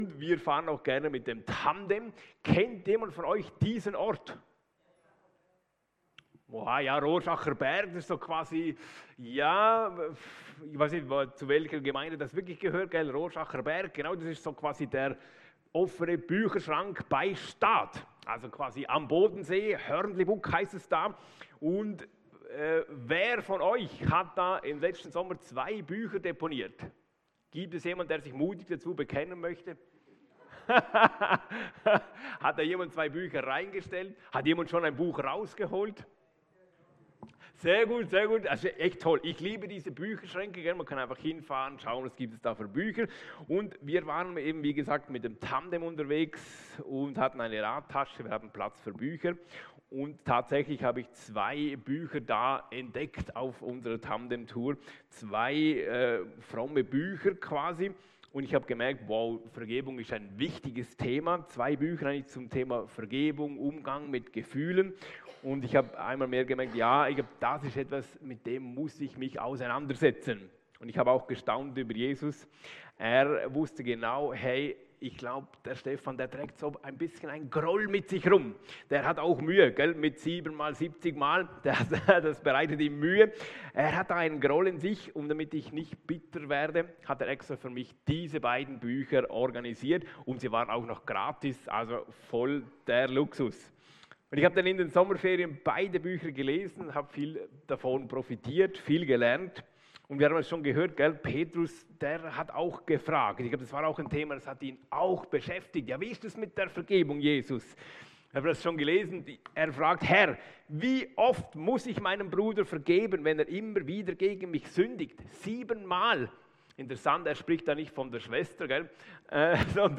Predigt
Hier hörst du die Predigten aus unserer Gemeinde.